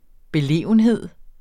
Udtale [ beˈleˀvənˌheðˀ ]